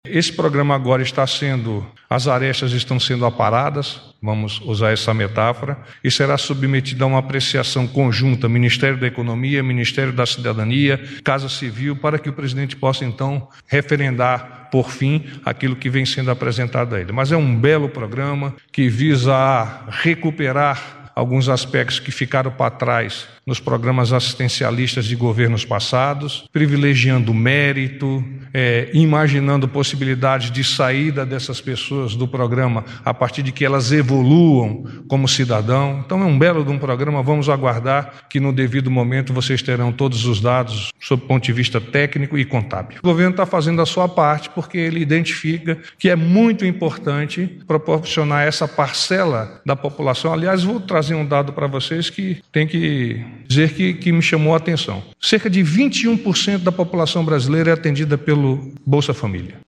MATÉRIA-BOLSA-FAMÍLIA-SERÁ-REFORMULADO-DIZ-PORTA-VOZ-DO-GOVERNO.mp3